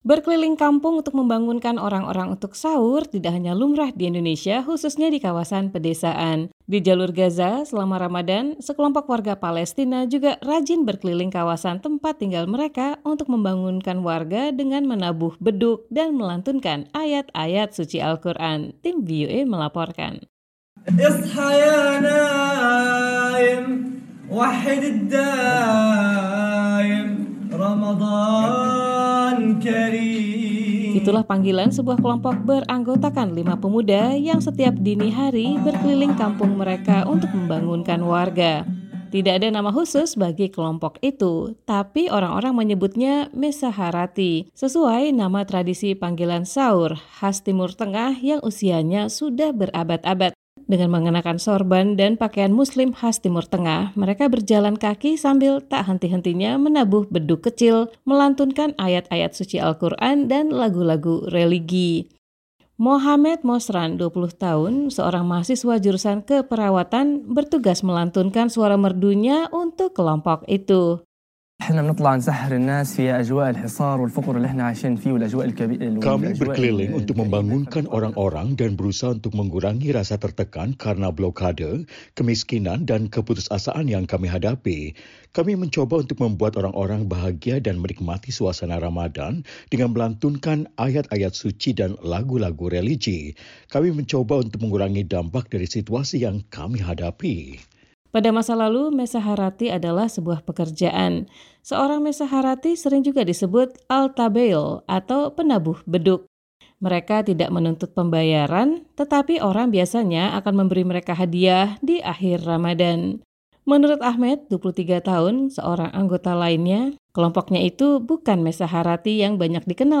Dengan mengenakan serban dan pakaian Muslim khas Timur Tengah, mereka berjalan kaki sambil tak henti-hentinya menabuh beduk kecil, melantunkan ayat-ayat suci Al-Qur'an, dan lagu-lagu religi.